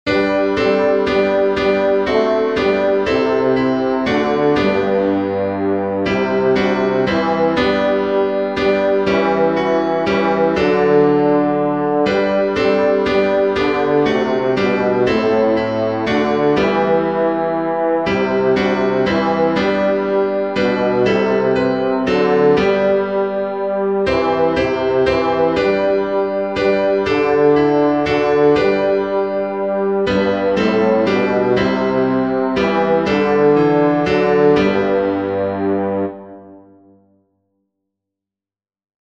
st_catherine-bass1.mp3